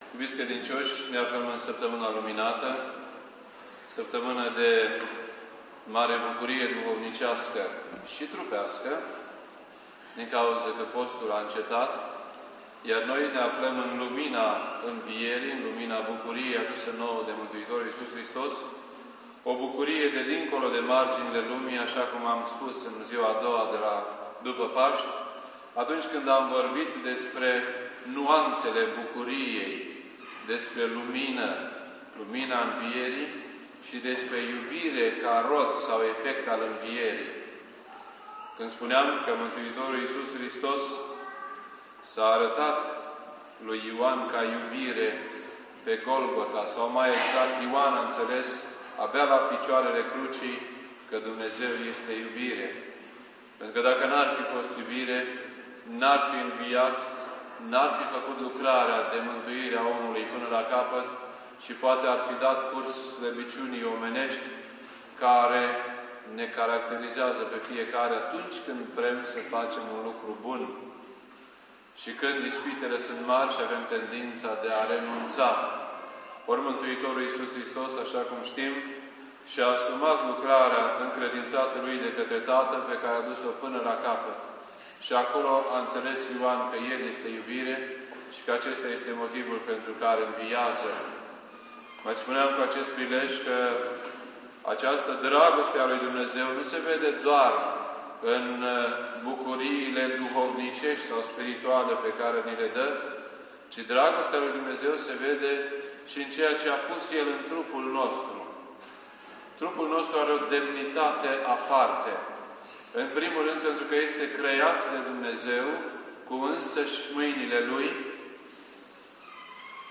This entry was posted on Friday, April 13th, 2007 at 10:19 AM and is filed under Predici ortodoxe in format audio.